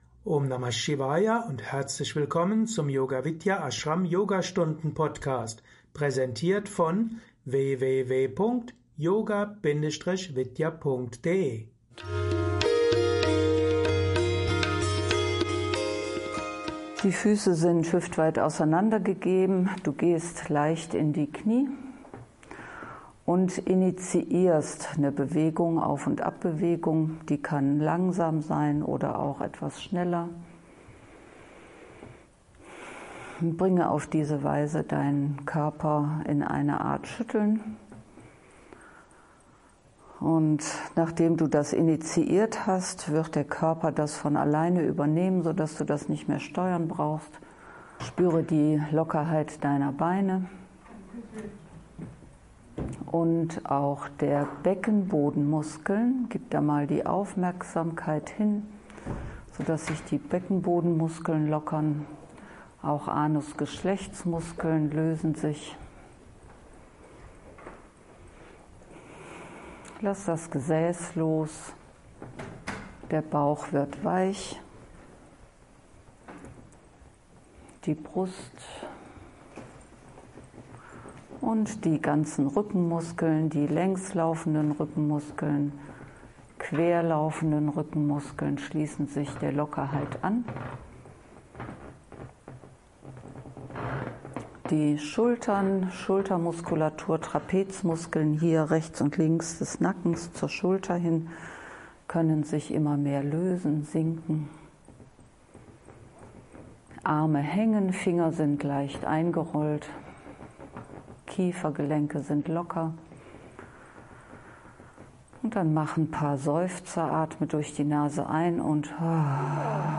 Viel Spaß mit dieser Yogastunde zur Stimulierung und Harmonisierung des Vagusnervs.